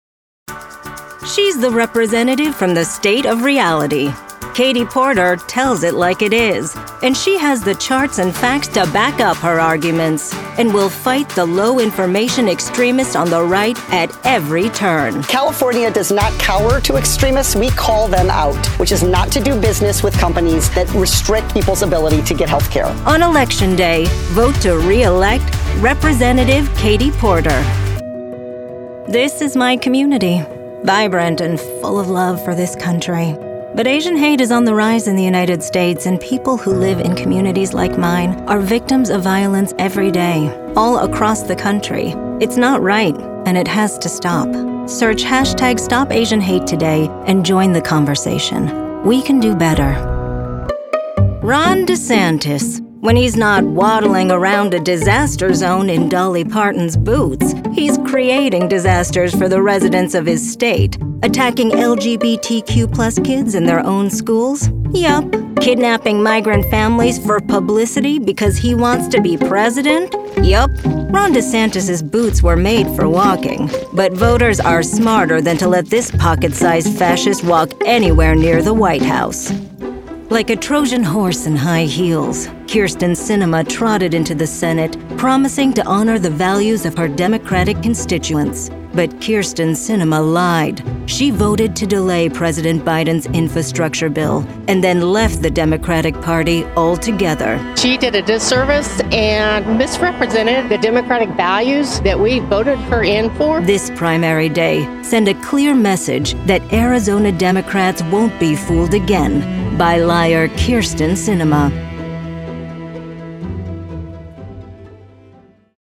Political Voice Demos
Professional Female Political Voiceover
• Home Studio